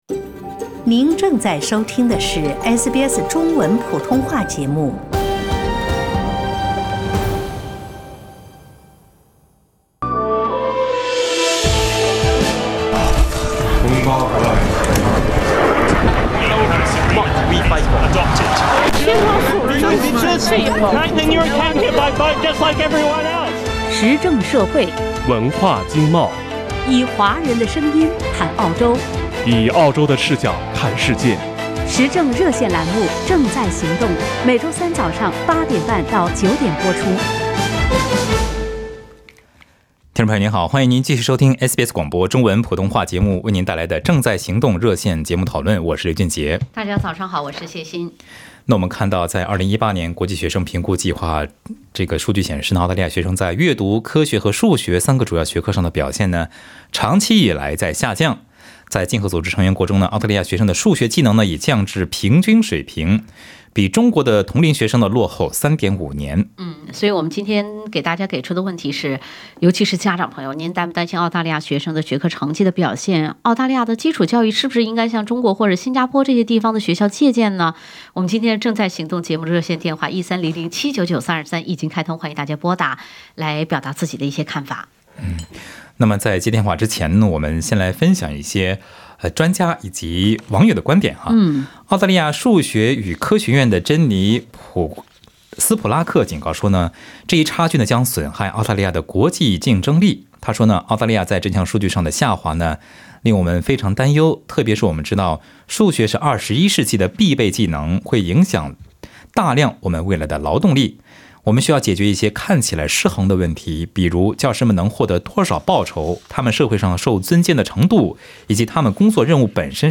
澳洲基础教育真落后了吗？热线讨论【正在行动】